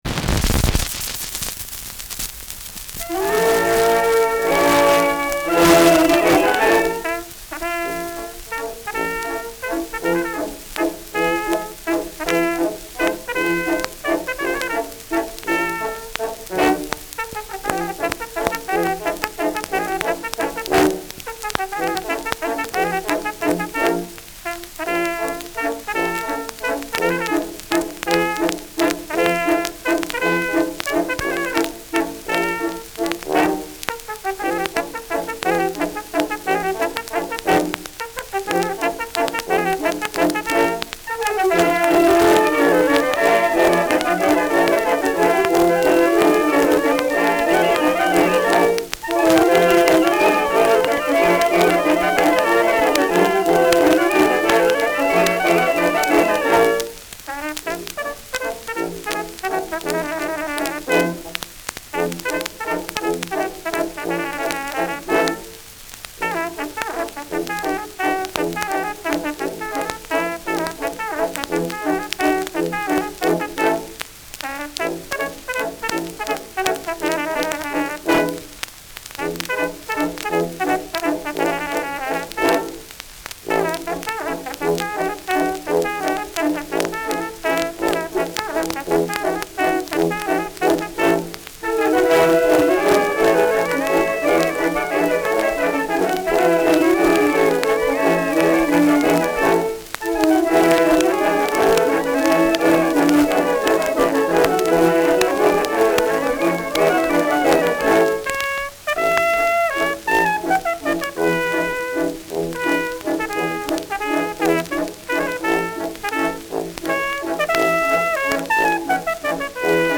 Schellackplatte
Abgespielt : Durchgehend stärkeres Knacken
Militärmusik des k.b. Leib-Regiments, München (Interpretation)